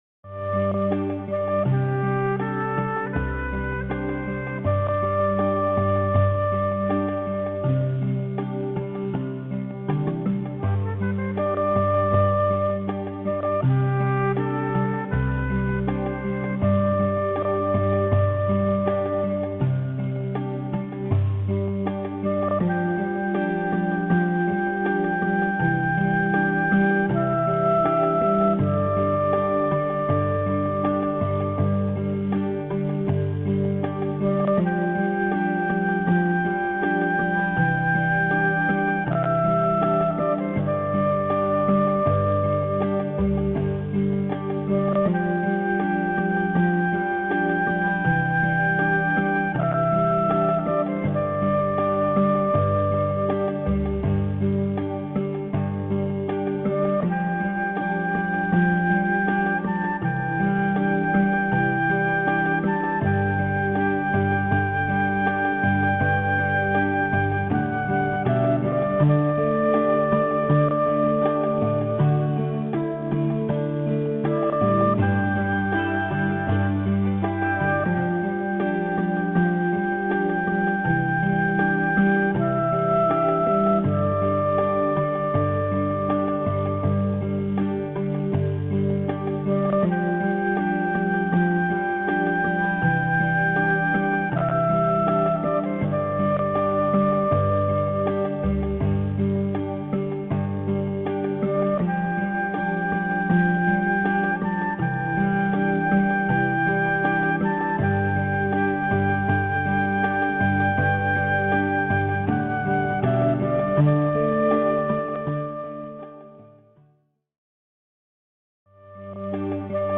Polycomhold.mp3